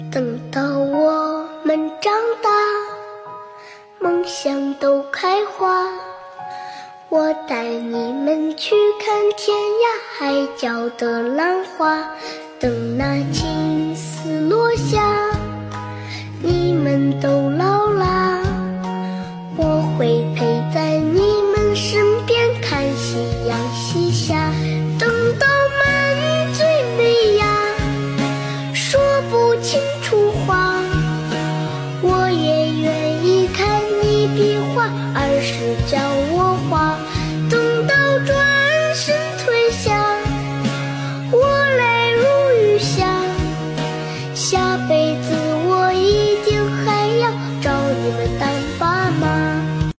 Nhạc Hoa